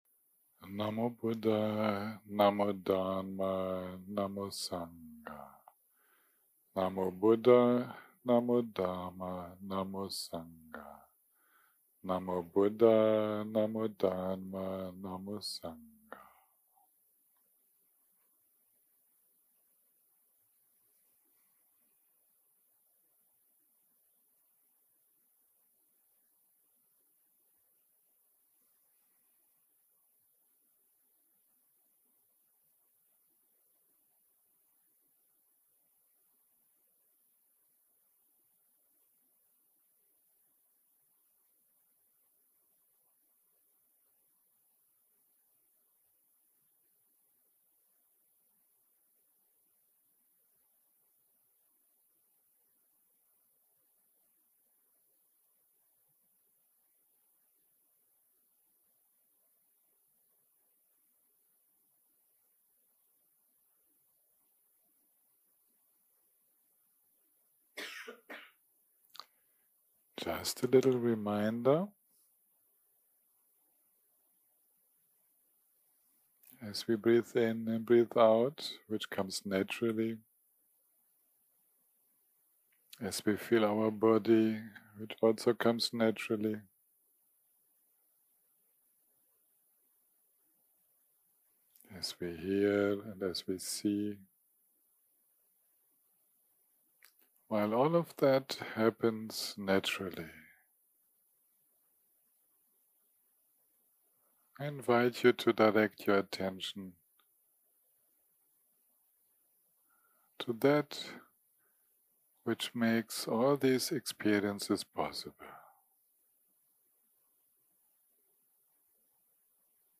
יום 7 - הקלטה 34 - ערב - הנחיות מדיטציה - Challenging careless thinking 0:00 0:00 סוג ההקלטה: סוג ההקלטה: שיחת הנחיות למדיטציה שפת ההקלטה: שפת ההקלטה: אנגלית